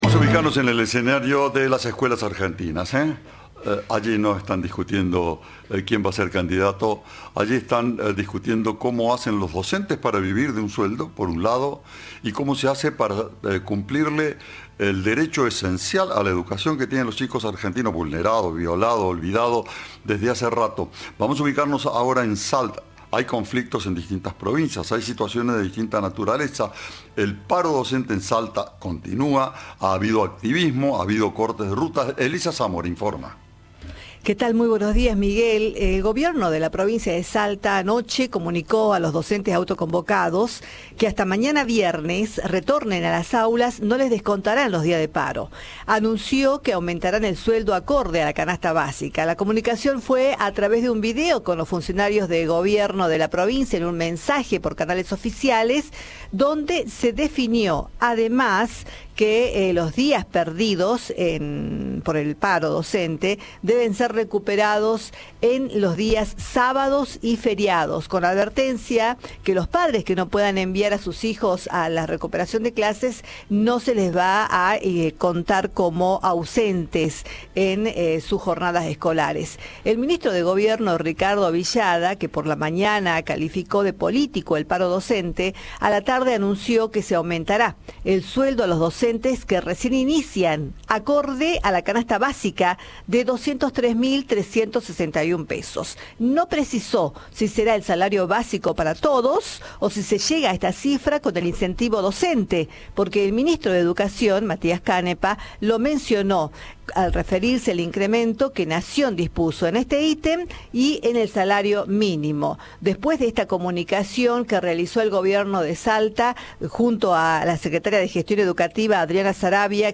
En conferencia de prensa el Gobierno de la Provincia de Salta anunció que tras cinco semanas de paro docente y de diferentes acciones y cortes en las rutas provinciales, resolvió brindar un aumento y no descontar de los sueldos los días perdidas.
Informe